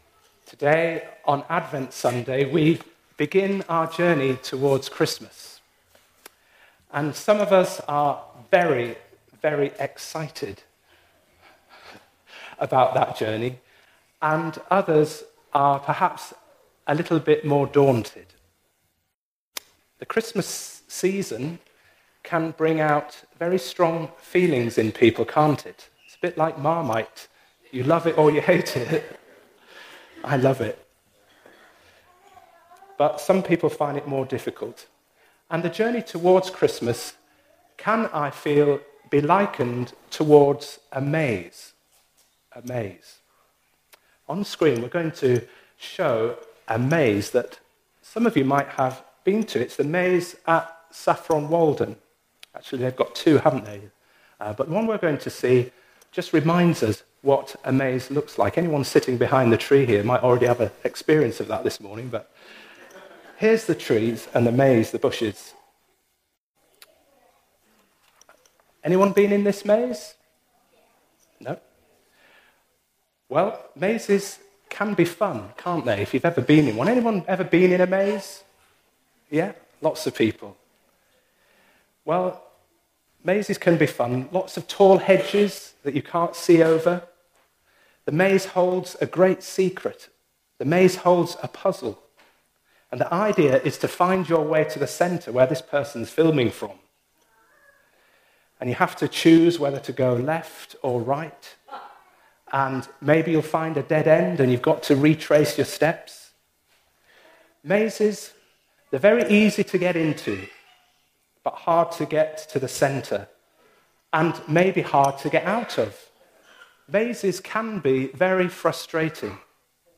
A service accessible to all ages with contemporary worship.
1 Corinthians 1:3-6,7-9 Service Type: All Age Worship Walking into Stillness